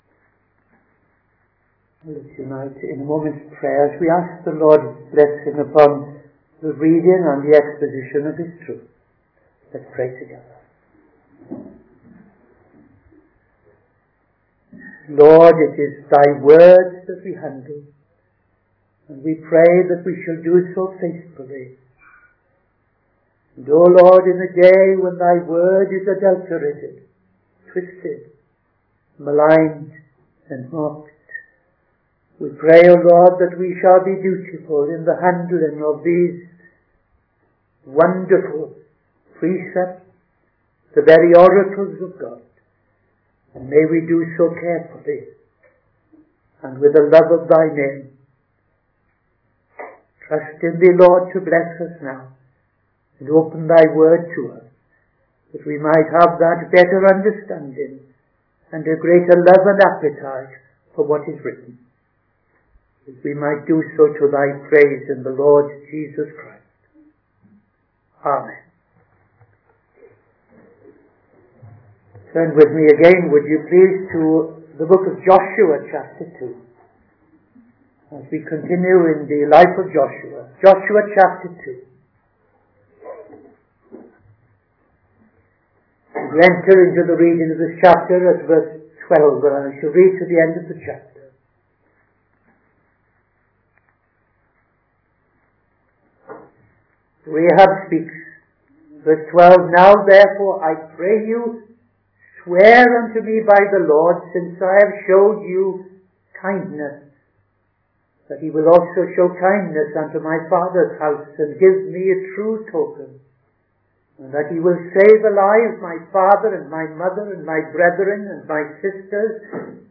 Midday Sermon - TFCChurch
Midday Sermon 2nd November 2025